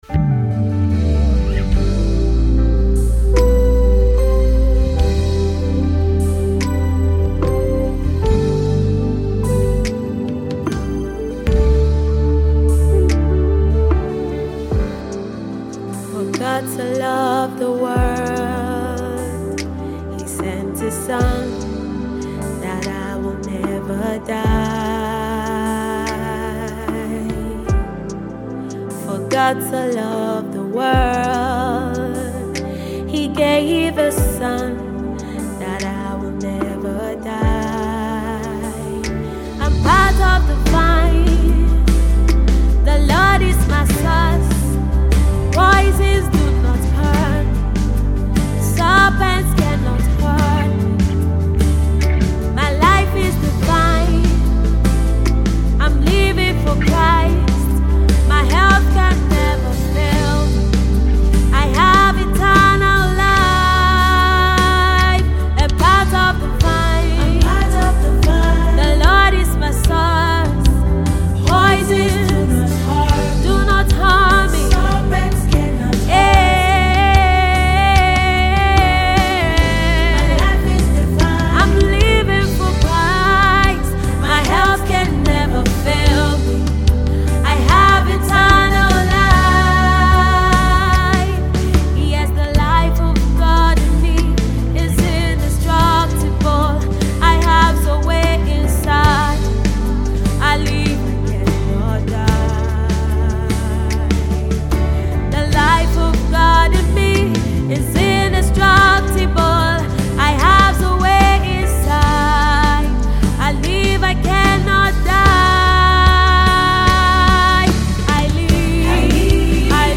Gospel Band
Gospel music